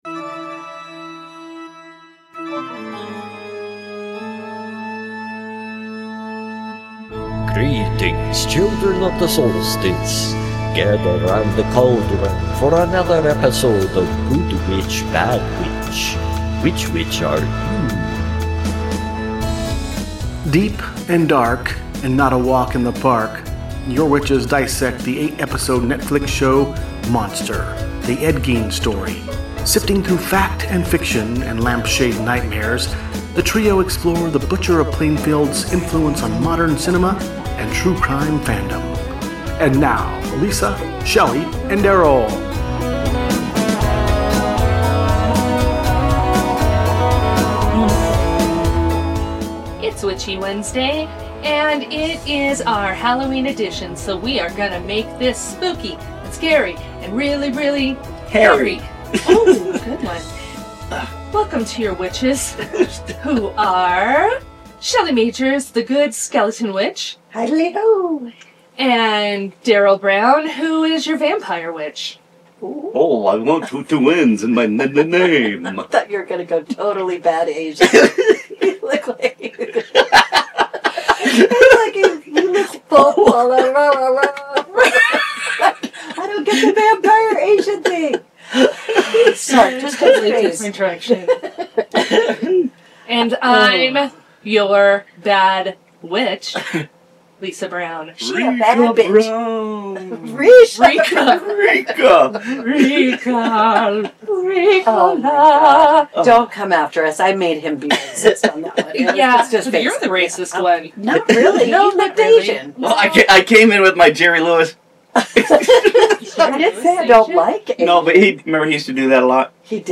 Two polar opposite best friends interview fascinating guests and dish the dirt. Topics range from urban legends and the metaphysical to true crime, music history and crazy animal facts.